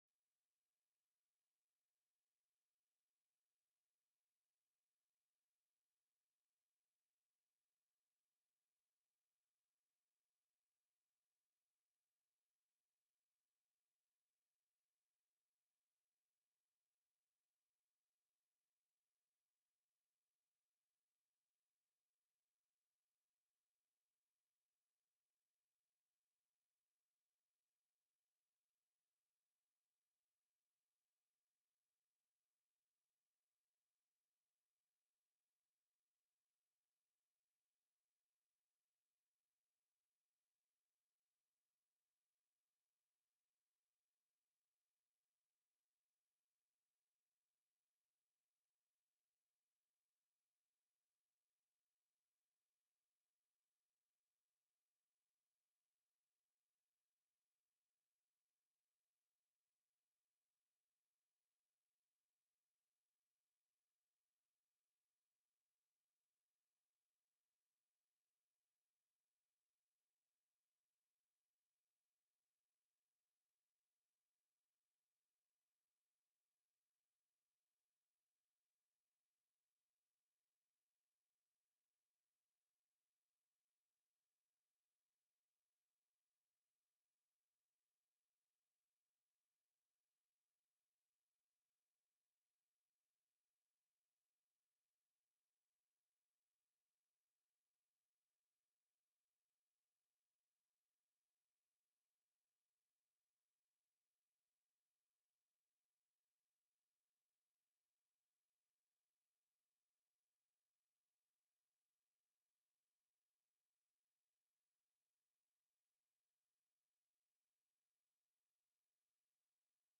The audio recordings are captured by our records offices as the official record of the meeting and will have more accurate timestamps.
+= HJR 20 HIRING OF APPRENTICES/VETERANS TELECONFERENCED